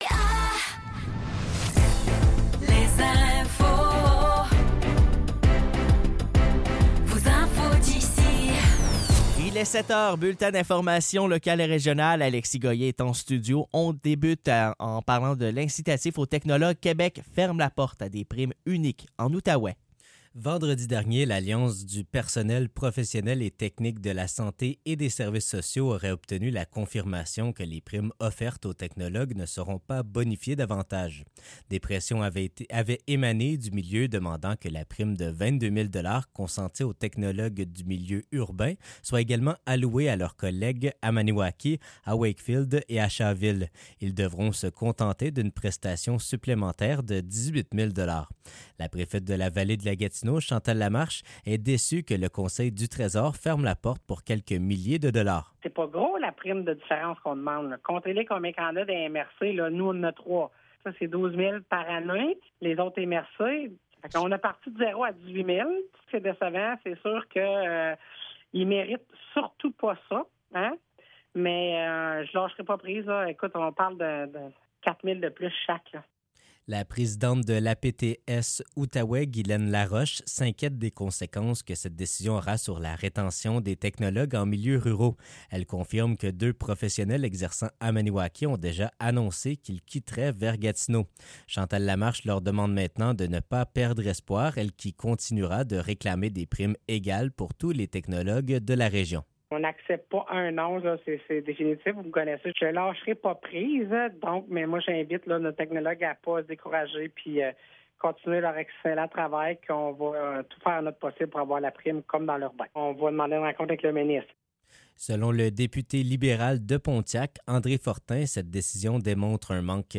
Nouvelles locales - 4 septembre 2024 - 7 h